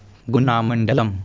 शृणु) /ˈɡʊnɑːməndələm/) (हिन्दी: गुना जिला, आङ्ग्ल: Guna district) इत्येतत् भारतस्य मध्यभागे स्थितस्य मध्यप्रदेशराज्यस्य ग्वालियरविभागे अन्तर्गतं किञ्चन मण्डलम् अस्ति ।